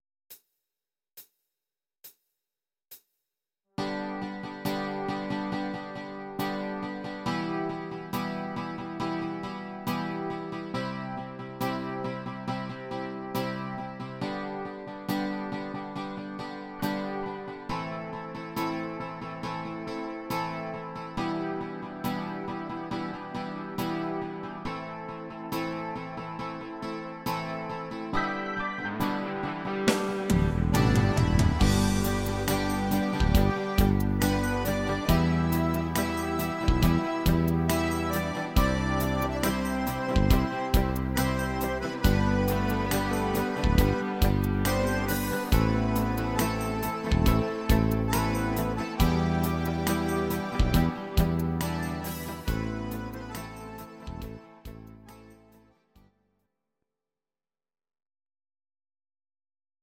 Audio Recordings based on Midi-files
Pop, German, 2000s, Volkstï¿½mlich